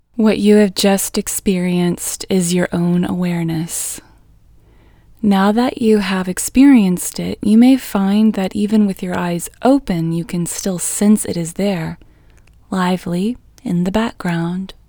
QUIETNESS Female English 18